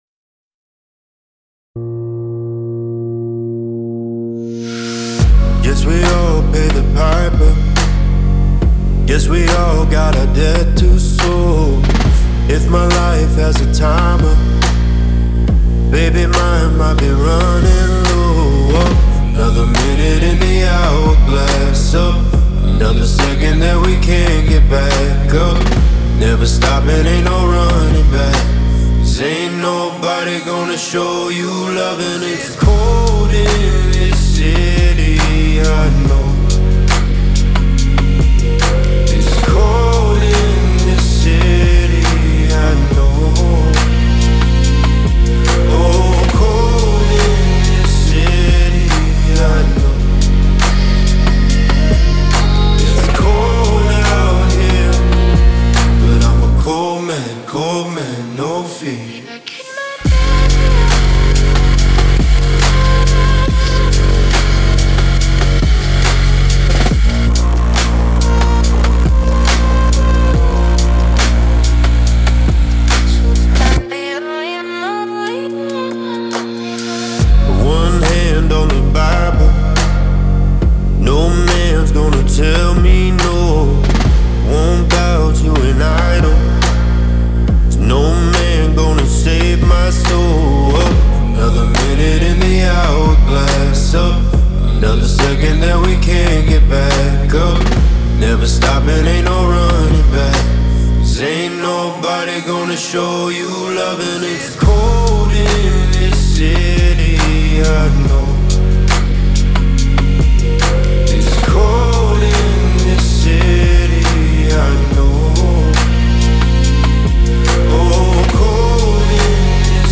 Pop
Bb Major